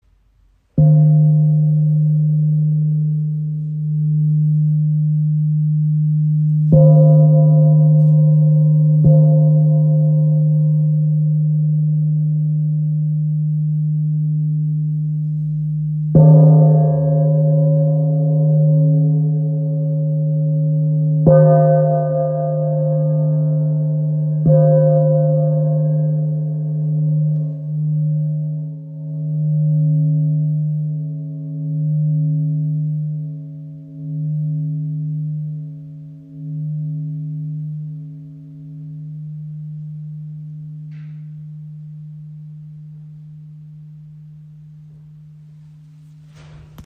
Gong | Material Edelstahl | Ø 50 cm im Raven-Spirit WebShop • Raven Spirit
Klangbeispiel
Der Sound ist voluminös und erinnert im Aufbau an übereinander liegende Klangebenen. So kann ein Soundgebilde aus Bass und Obertönen entstehen. Solche Gongs mit Rand erzeugen einen sehr meditativen Gesamtklang.